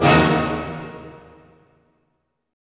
Index of /90_sSampleCDs/Roland LCDP08 Symphony Orchestra/HIT_Dynamic Orch/HIT_Orch Hit Min
HIT ORCHM04L.wav